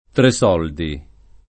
[ tre S0 ldi ]